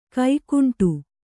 ♪ kaikuṇṭu